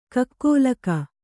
♪ kakkōlaka